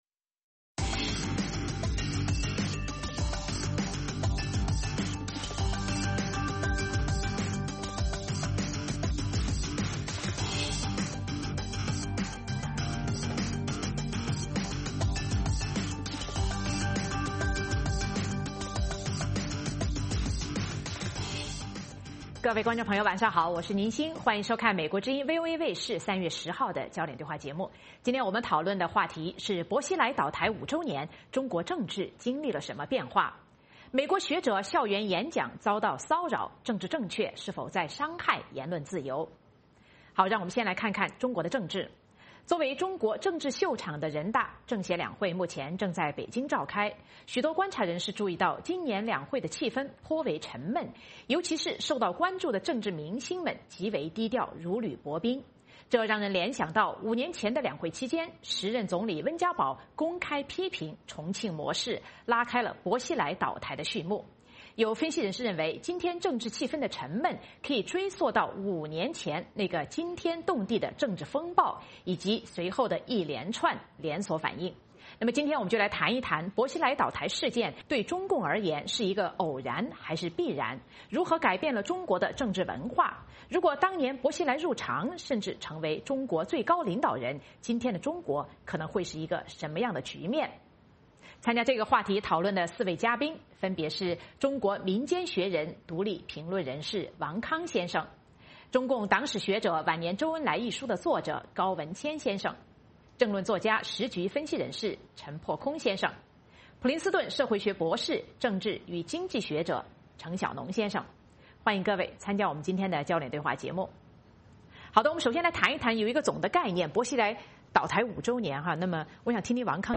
《焦点对话》节目追踪国际大事、聚焦时事热点。邀请多位嘉宾对新闻事件进行分析、解读和评论。或针锋相对、或侃侃而谈。